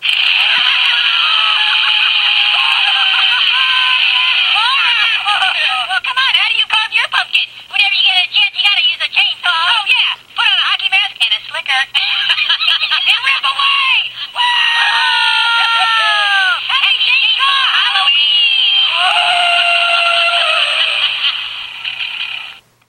Happy Chainsaw Halloween! is a hoops&yoyo greeting card with sound made for halloween.